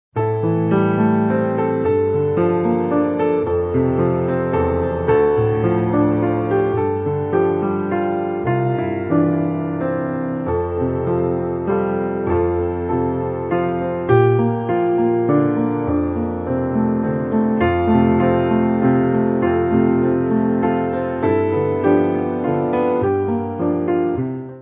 Mainly Piano
Easy Listening